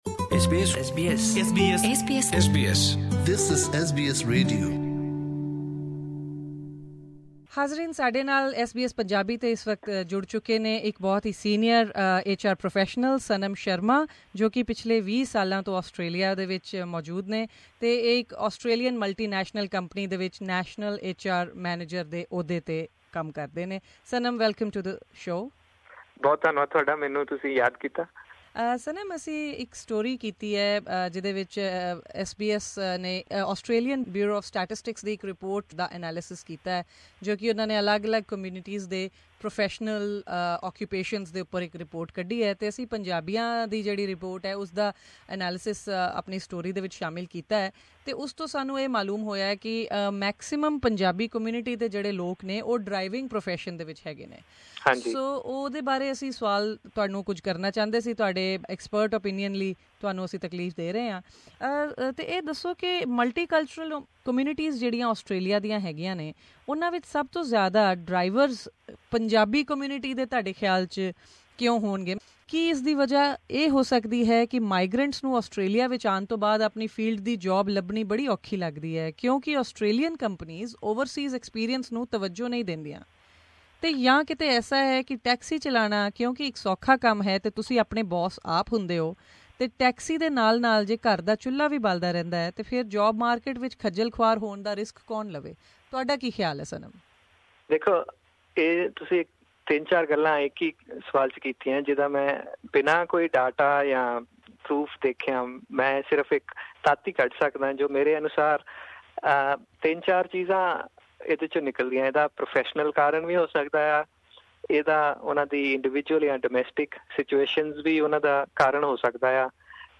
ਓਹਨਾ ਦਾ ਤਫ਼ਸੀਲੀ ਜਵਾਬ ਸੁਣੋ ਇਸ ਇੰਟਰਵਿਊ ਵਿਚ...